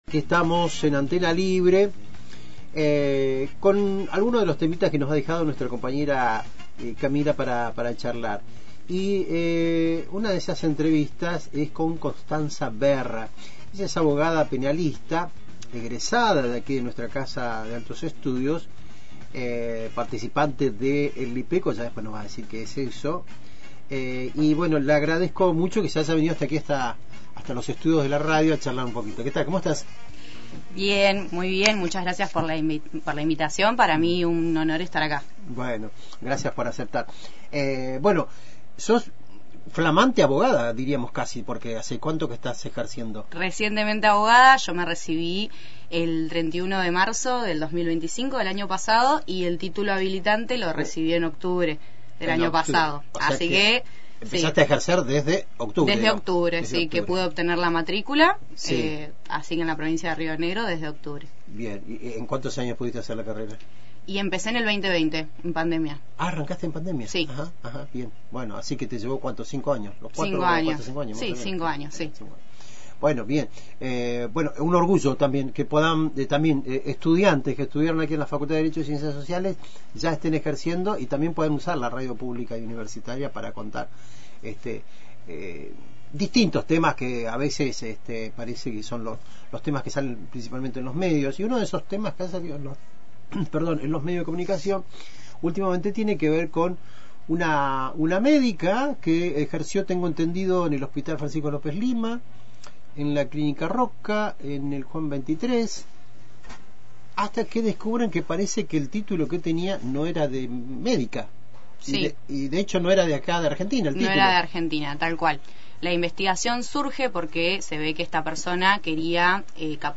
la abogada penalista